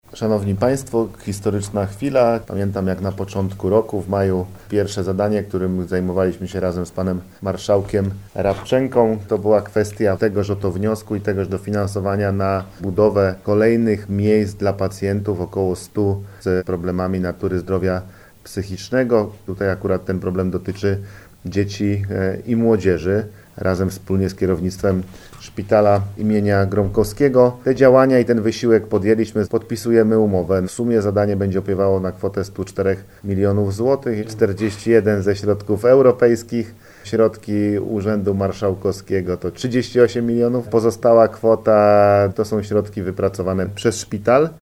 Mówi Paweł Gancarz, Marszałek Województwa Dolnośląskiego.